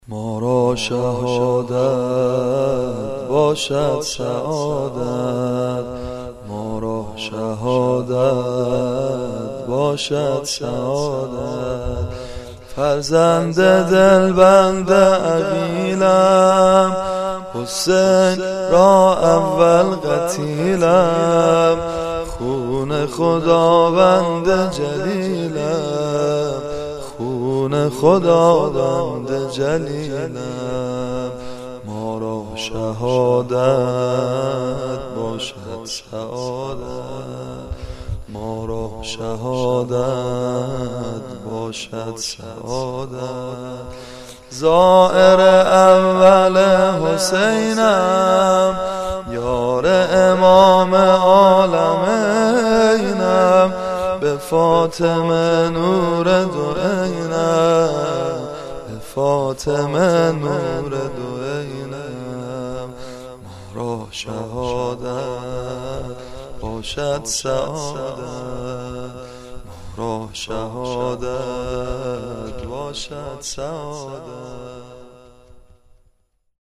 در استودیوی عقیق